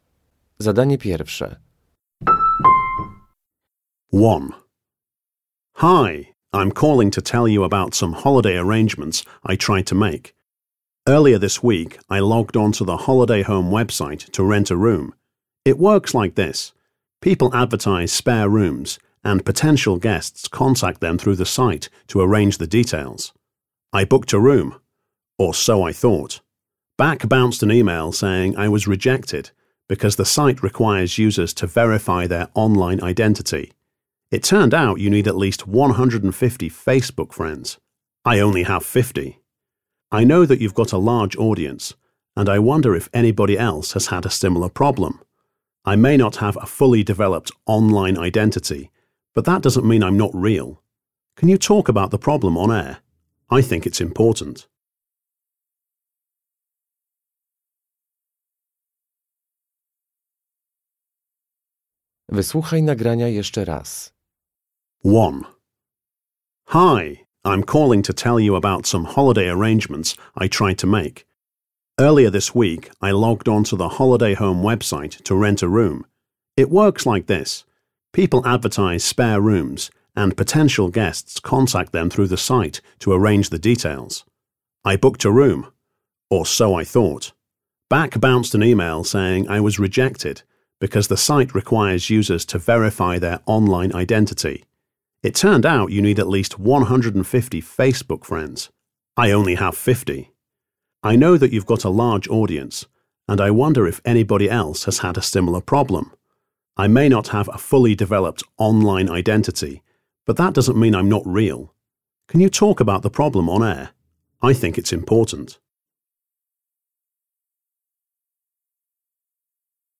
Uruchamiając odtwarzacz usłyszysz dwukrotnie trzy teksty.